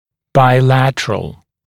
[baɪ’lætərəl][бай’лэтэрэл]двусторонний